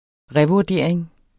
Udtale [ ˈʁεvuɐ̯ˌdeˀɐ̯eŋ ]